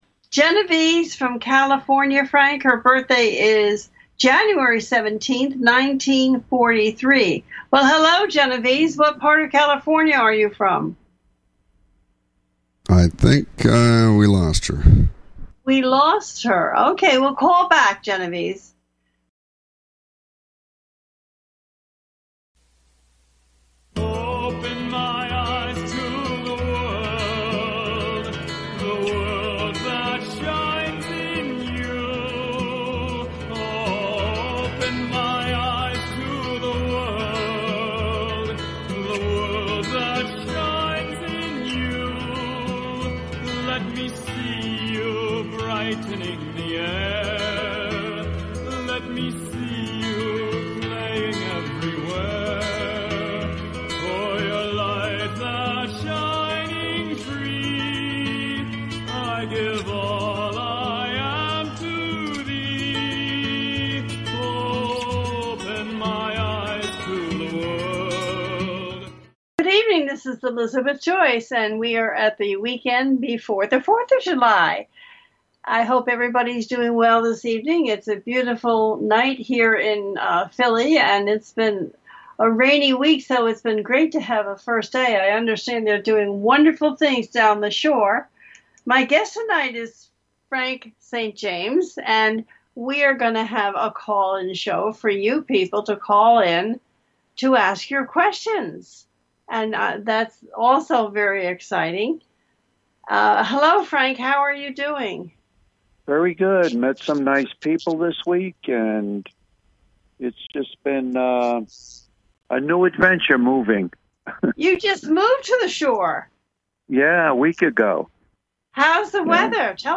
Let’s Find Out brings to its listeners illuminating and enthralling exploration of the connection between our minds and our bodies. This show brings a series of fascinating interviews with experts in the field of metaphysics.
The listener can call in to ask a question on the air.